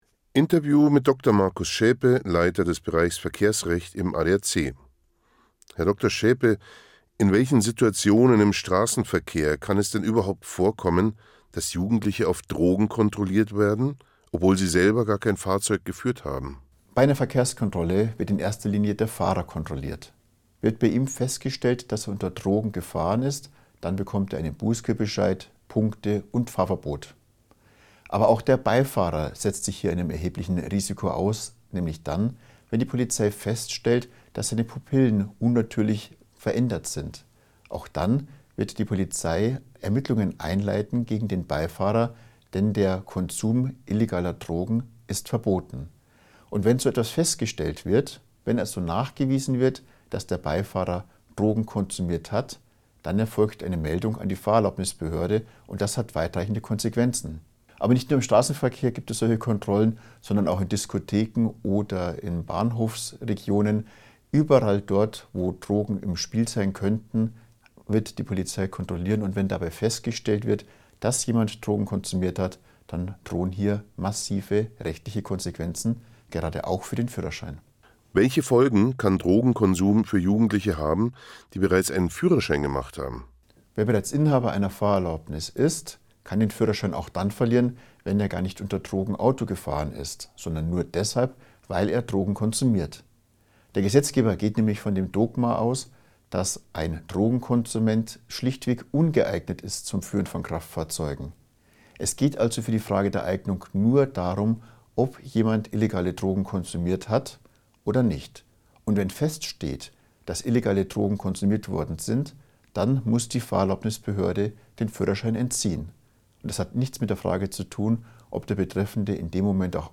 Interview zu diesem Thema